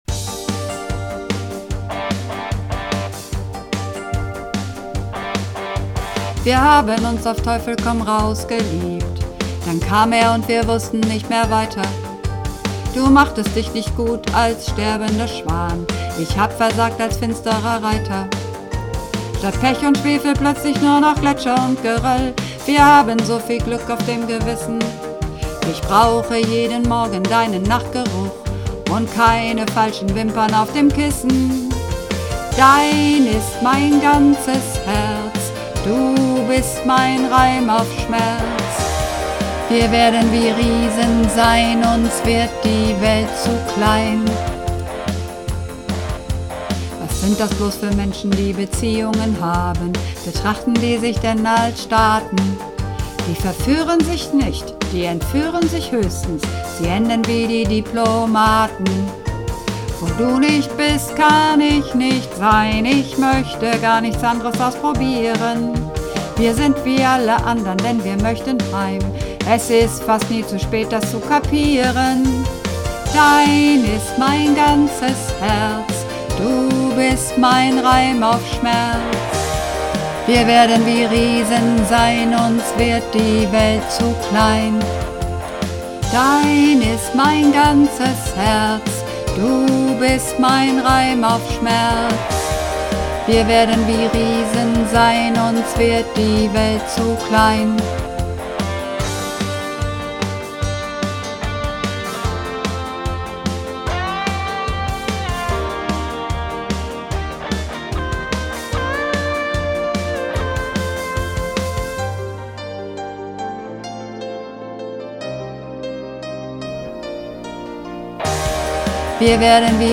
Übungsaufnahmen - Dein ist mein ganzes Herz
Dein ist mein ganzes Herz (Bass)
Dein_ist_mein_ganzes_Herz__2_Bass.mp3